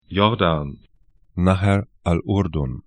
Pronunciation
Jordan 'jɔrdan Nahr al Urdunn 'nahɛr al ʊrdʊn ar Fluss / stream 32°53'N, 35°37'E